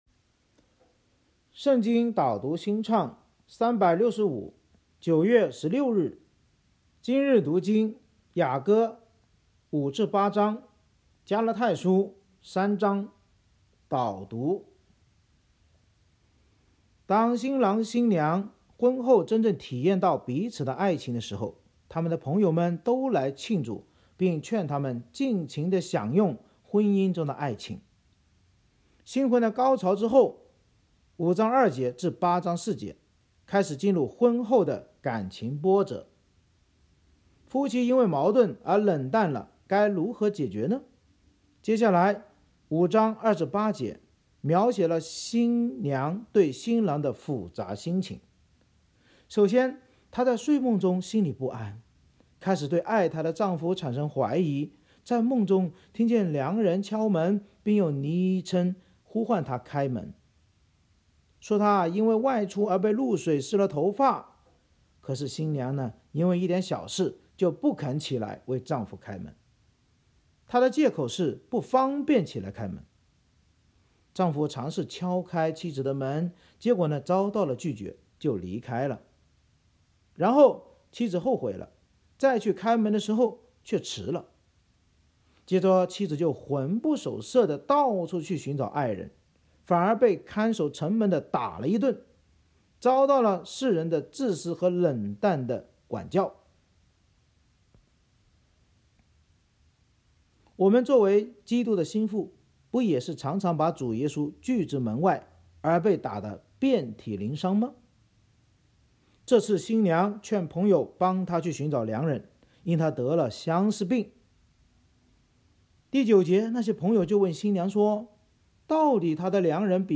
圣经导读&经文朗读 – 09月16日（音频+文字+新歌）